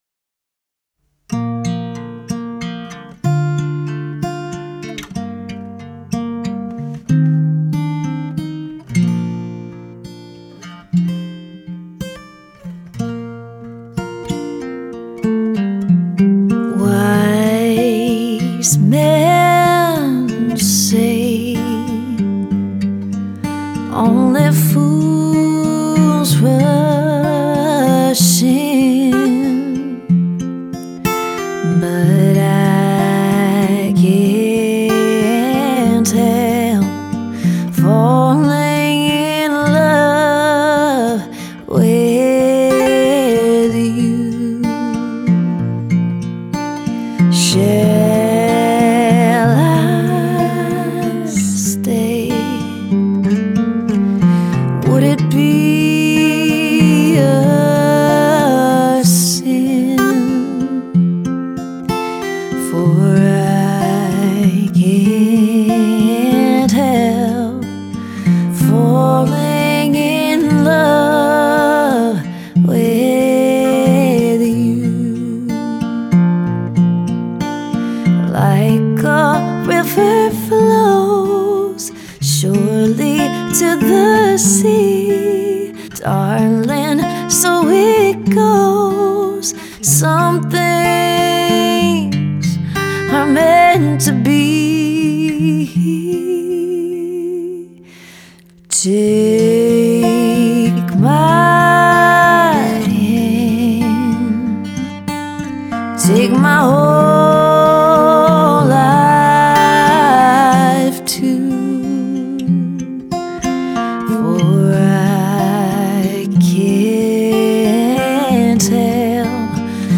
Dual Vocals | Looping | DJ